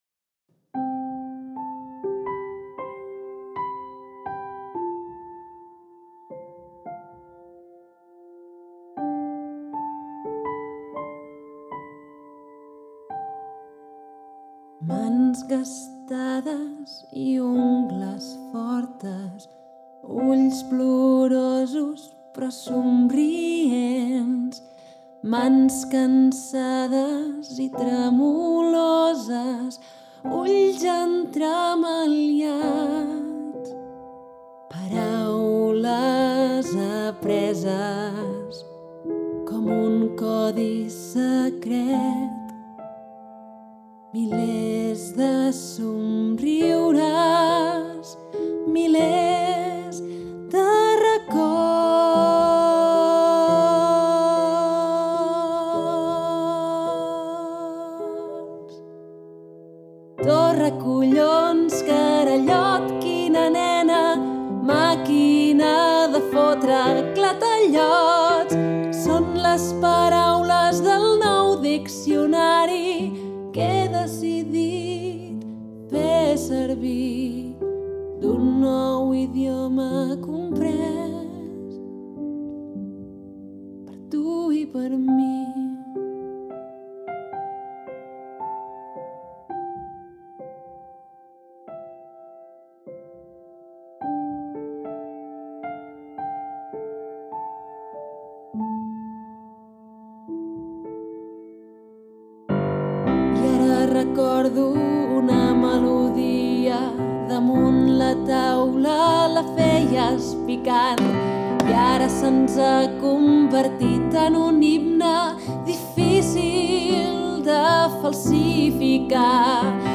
voz y piano